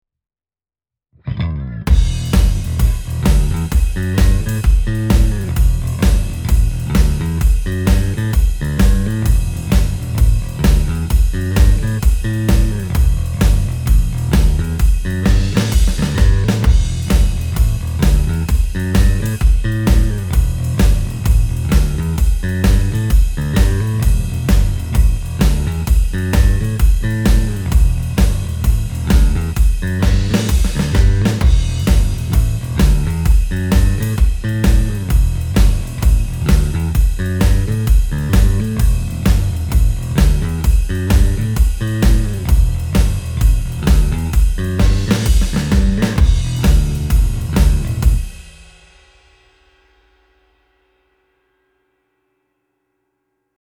I can only describe it as a "springy" mid-range presence Hopefully that makes more sense now... lol